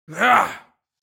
دانلود آهنگ نبرد 2 از افکت صوتی انسان و موجودات زنده
جلوه های صوتی